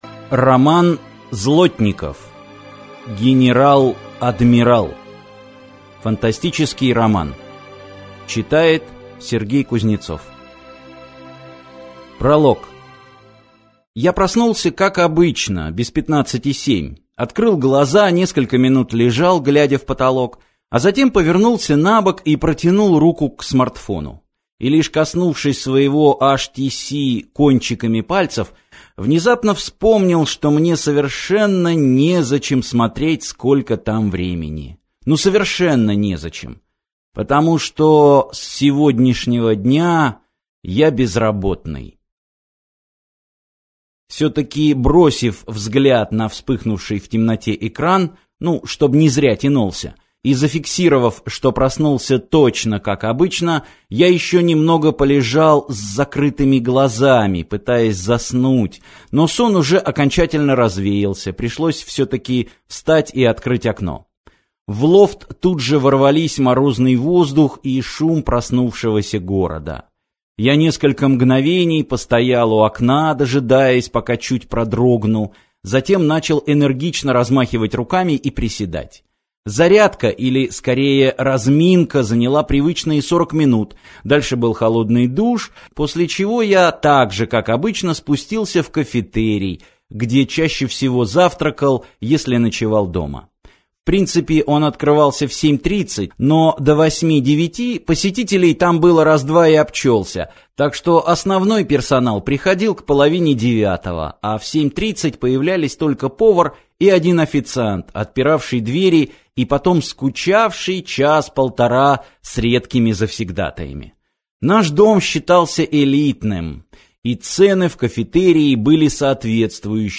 Аудиокнига Генерал-адмирал - купить, скачать и слушать онлайн | КнигоПоиск